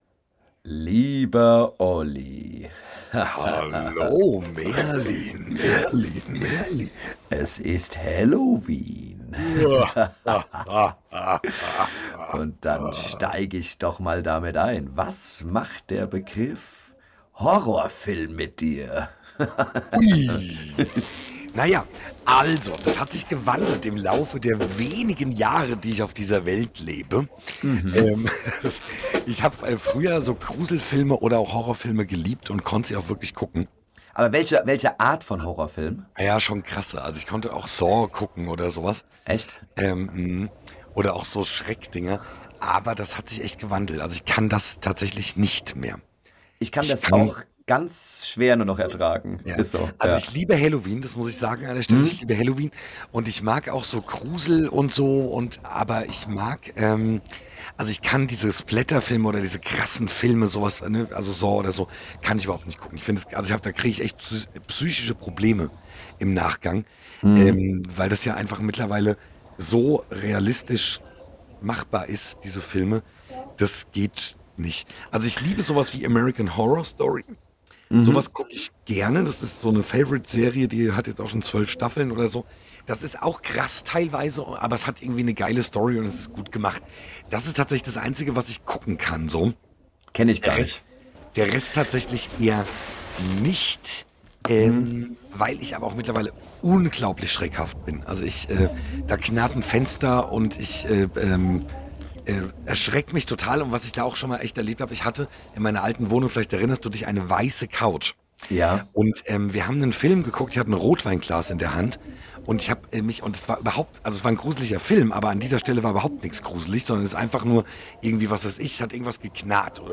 In dieser Folge sprechen die Moderatoren über die gruselige Welt der Käfer, die Angst vor dem Tod und die Traditionen rund um Halloween, einschließlich des Kürbisschnitzens. Eben aufgenommen und direkt online gestellt - ohne Schnitt und doppelten Boden.